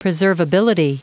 Prononciation du mot preservability en anglais (fichier audio)